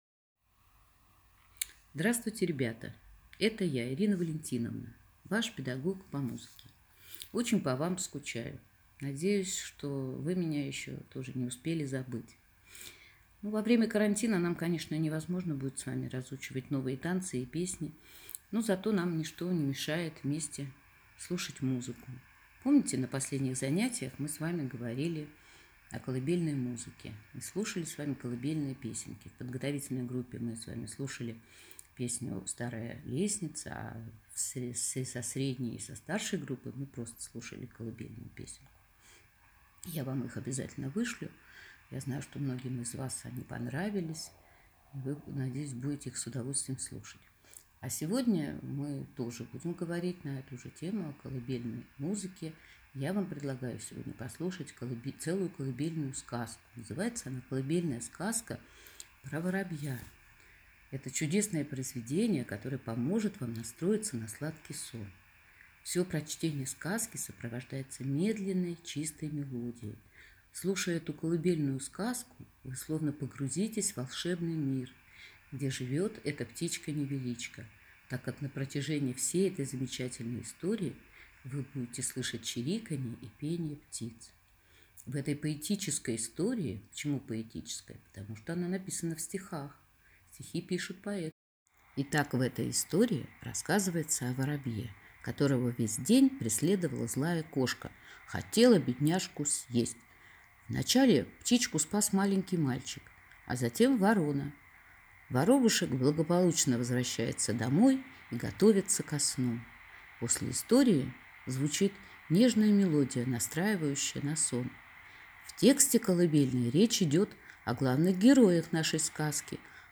Музыкальное занятие на тему “Колыбельные”